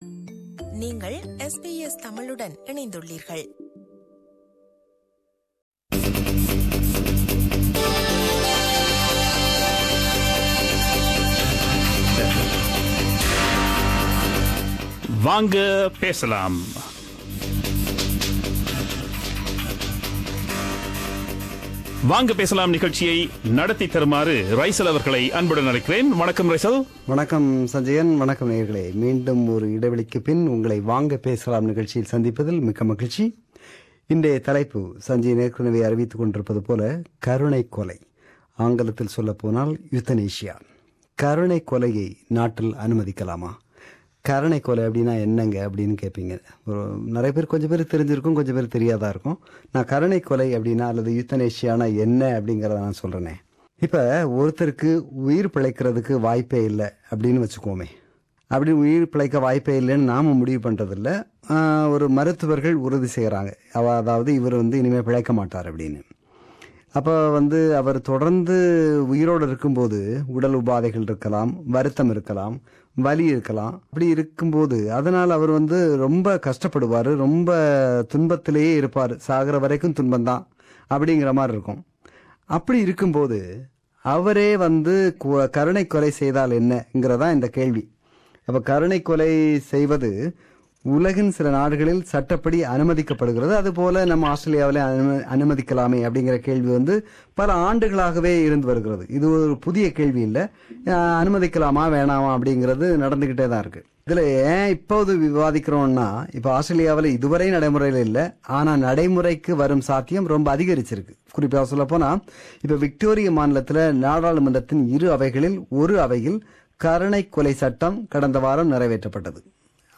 சில நேயர்களின் கருத்துக்களும்